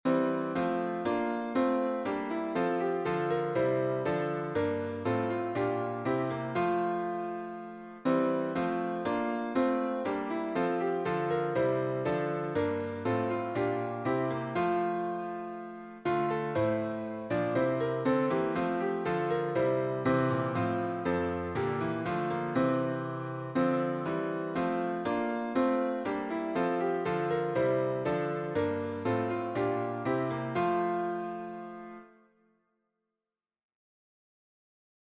English melody